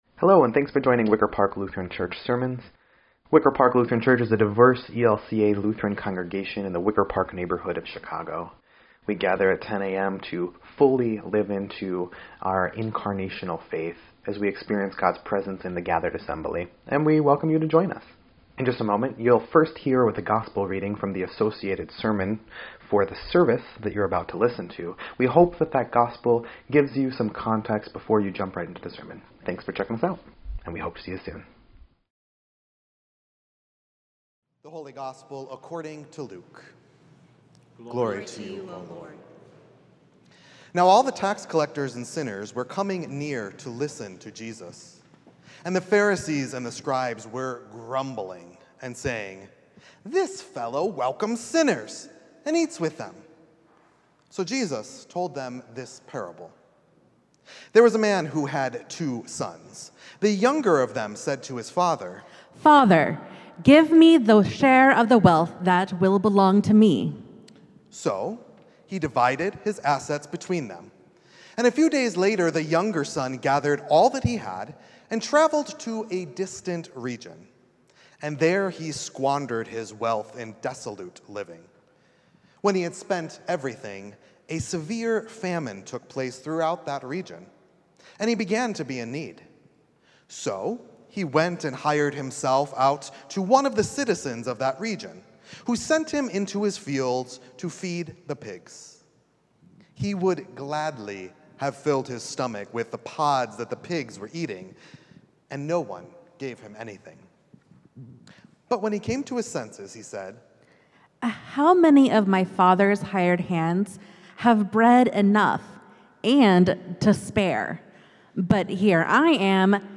3.30.25-Sermon_EDIT.mp3